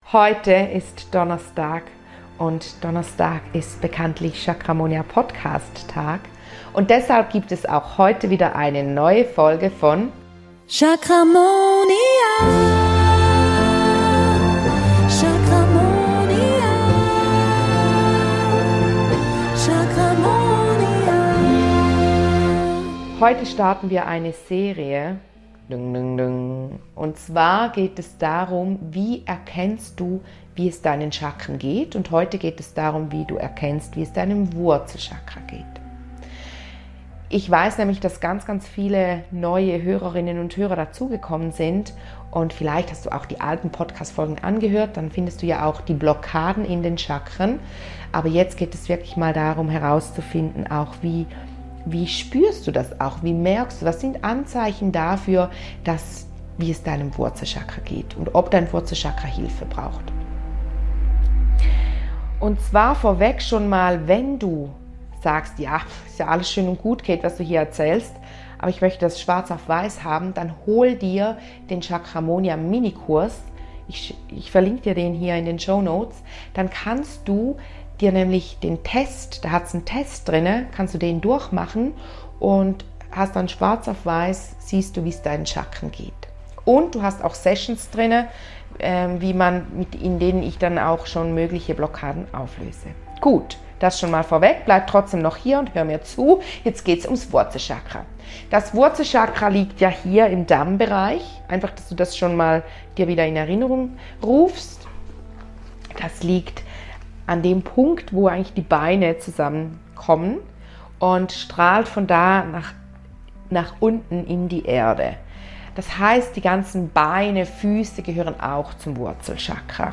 Es wird auch Wurzelchakra genannt, das Wurzelchakra steuert alle Themen und Bereiche, die mit Urvertrauen, Sicherheit und unserer Lebenskraft einhergehen. Gemeinsam werden wir eine Erdende Meditatio npraktizieren, um deine Verbindung zu deinem Wurzelchakra zu vertiefen und dich auf dem Weg zu persönlichem Wachstum und innerer Erfüllung zu unterstützen.